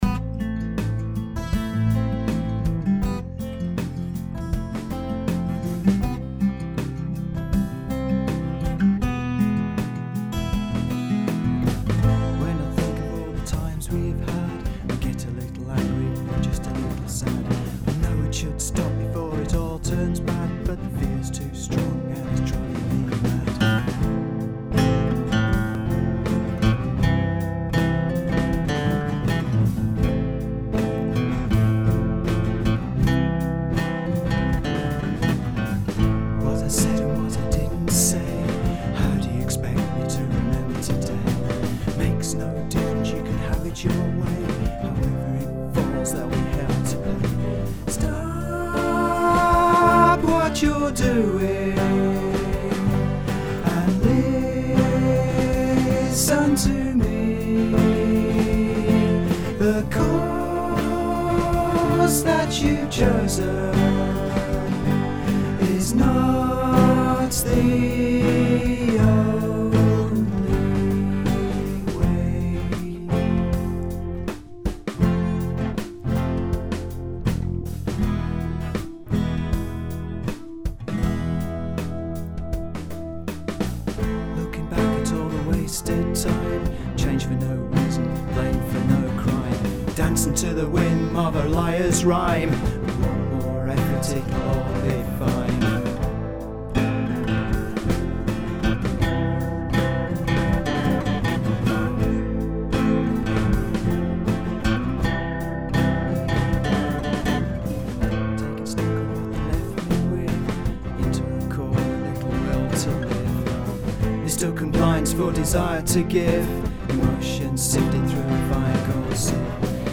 Time To Change (multi-track demo - 27/06/2012).
Warning: I attempt to sing on this one!